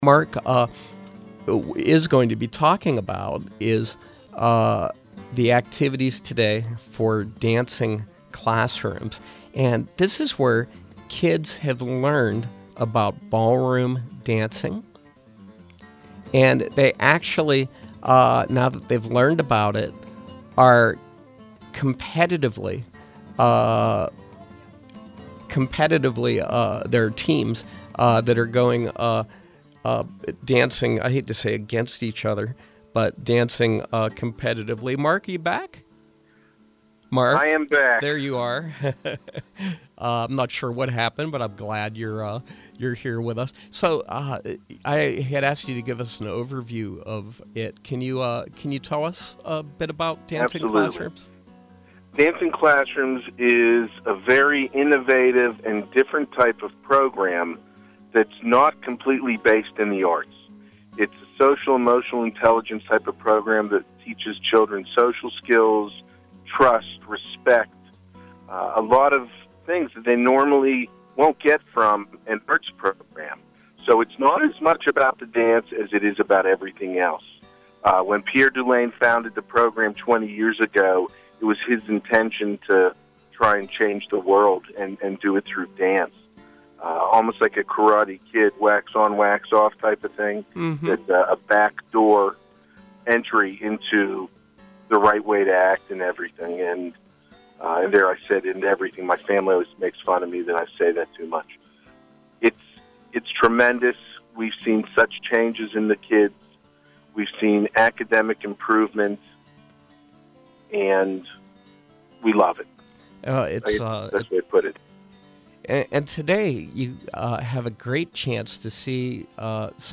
Home » Featured, Interviews